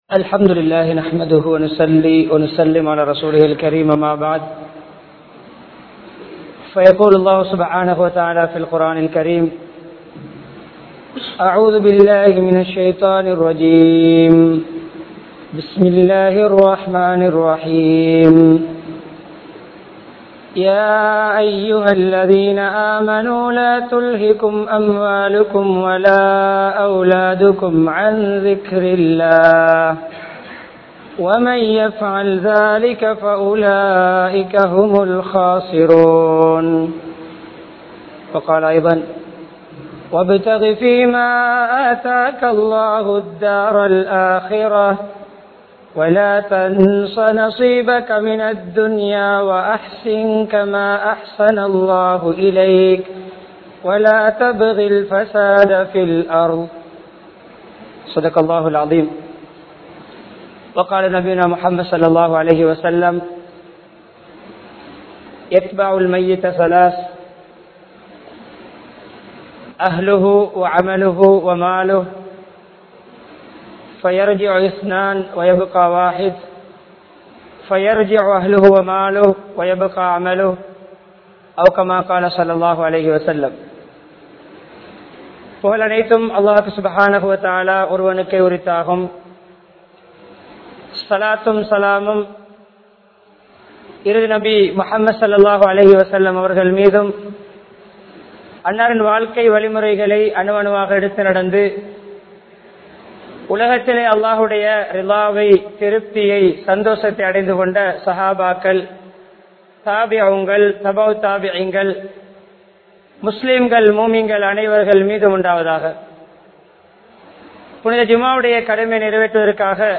Dheen & Dhunyaavukkidaielaana Viththiyaasam (தீண் & துண்யாவுக்கிடையிலான வித்தியாசம்) | Audio Bayans | All Ceylon Muslim Youth Community | Addalaichenai
Muhiyadeen Jumua Masjith